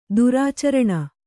♪ durācaraṇa